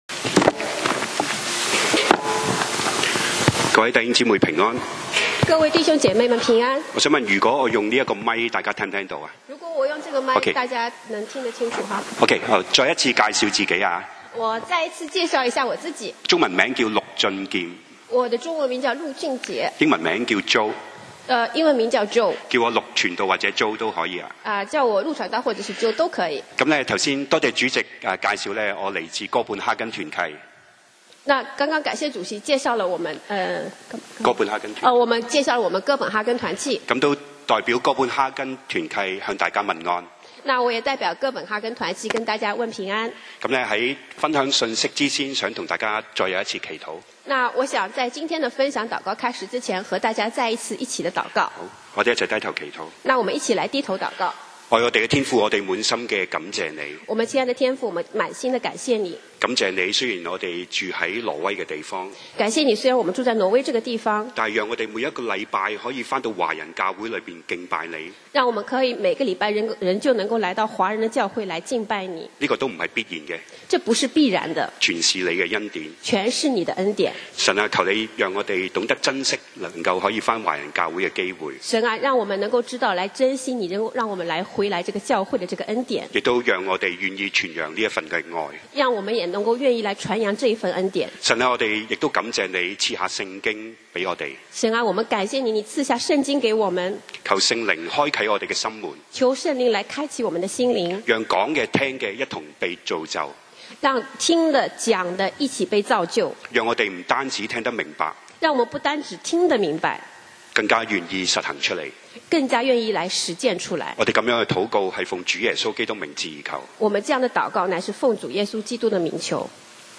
講道 Sermon 題目 Topic：務要傳福音 經文 Verses：提摩太後書4:1-5 1我在神面前，并在将来审判活人死人的基督耶稣面前，凭着他的显现和他的国度嘱咐你。